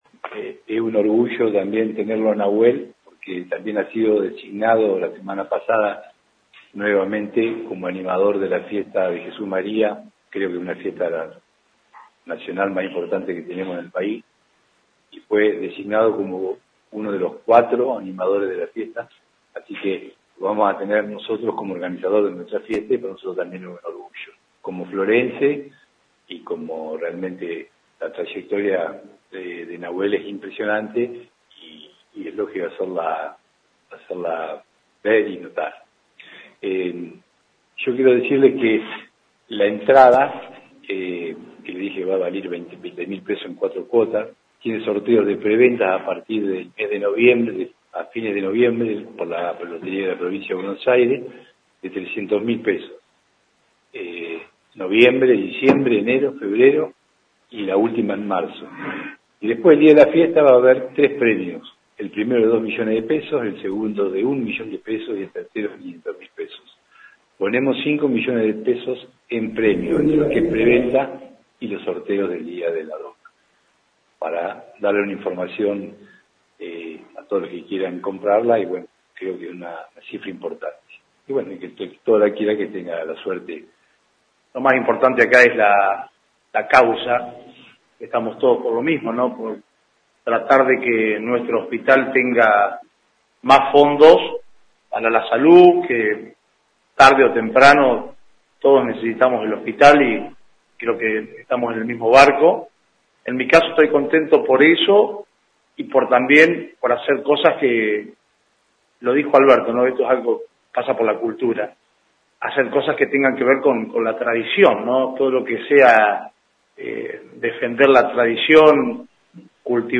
En conferencia de prensa que tuvo lugar en la mañana de este jueves en el SUM de la Asociación Cooperadora fue presentada oficialmente la 26ta. edición de la Fiesta del Hospital de Las Flores que se llevará a cabo los días 21, 22 y 23 de marzo en el campo de destrezas criollas del Centro Tradicionalista «La Tacuara».
Conferencia-parte-dos.mp3